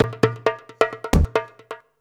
Index of /90_sSampleCDs/USB Soundscan vol.56 - Modern Percussion Loops [AKAI] 1CD/Partition C/17-DJEMBE133
133DJEMB07.wav